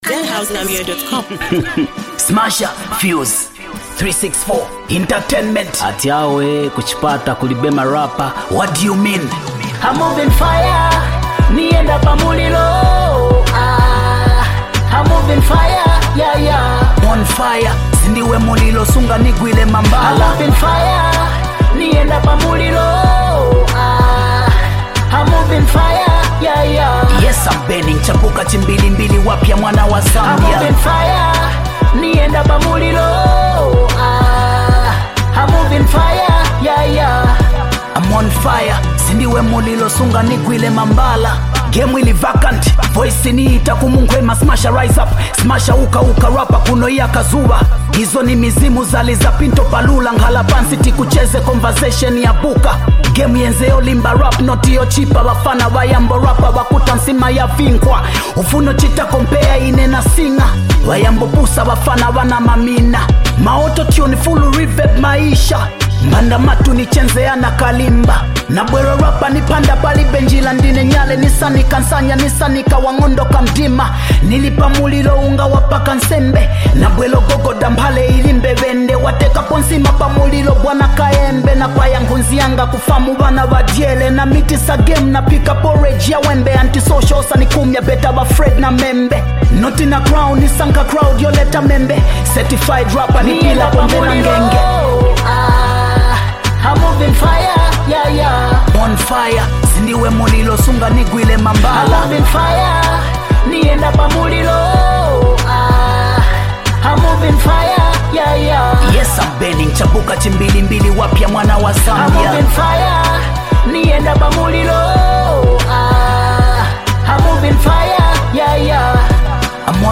he’s back with another explosive Hip-hop banger.
Bars, heat, and raw energy